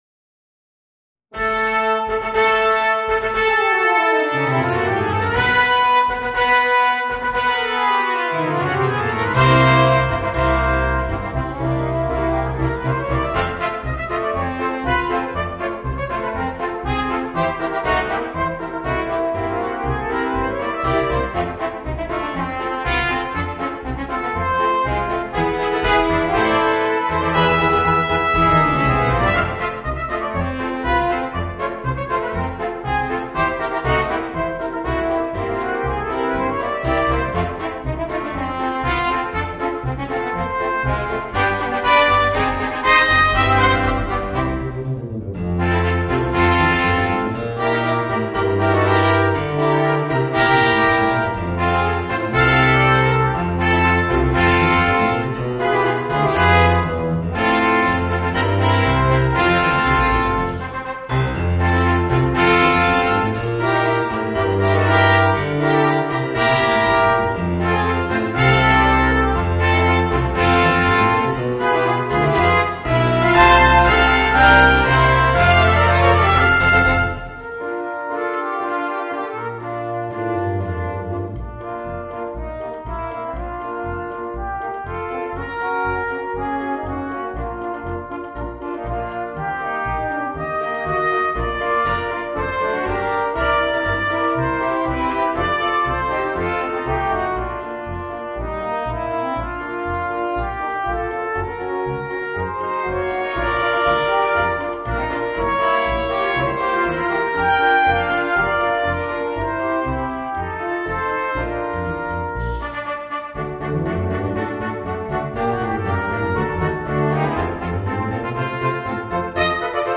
Voicing: 2 Trumpets, 2 Trombones and Drums